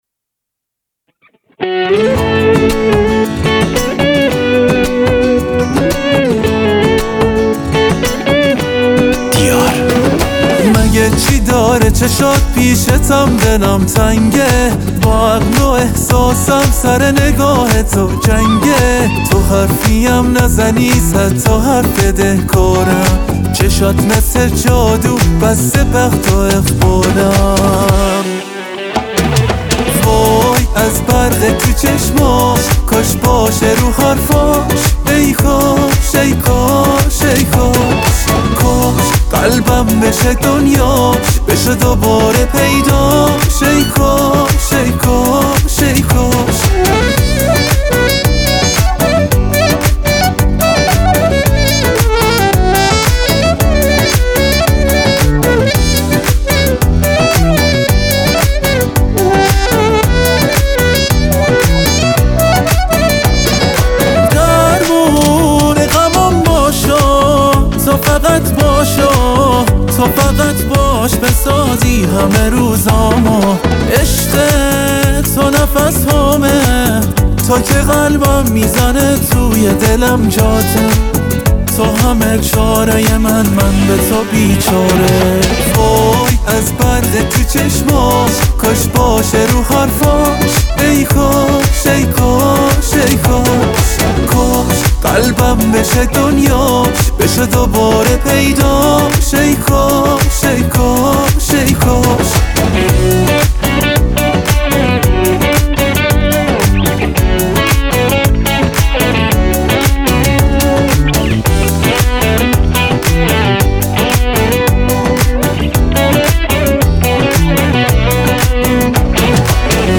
پاپ
آهنگ با صدای زن
آهنگ غمگین